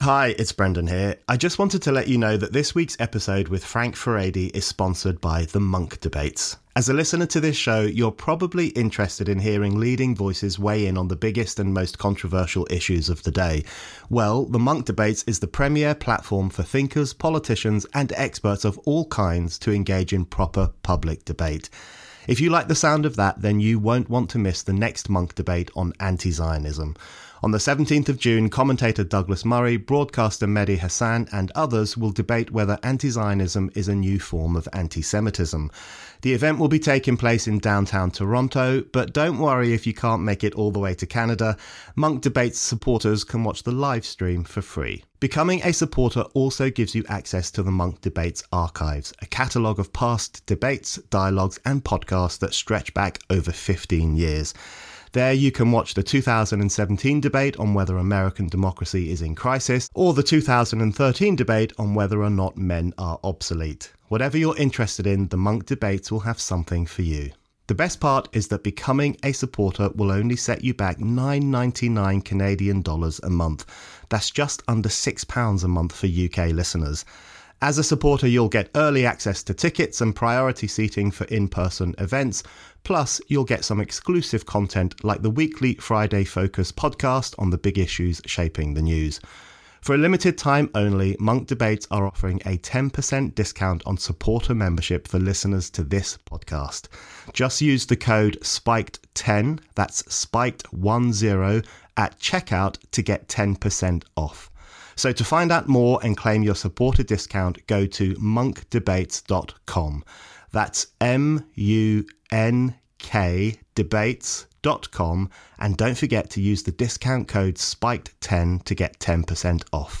Frank Furedi, executive director of MCC Brussels, returns for the latest episode of The Brendan O’Neill Show. Frank and Brendan discuss the Tory-Labour duopoly, the growing rift between the people and the elites and why the culture war matters.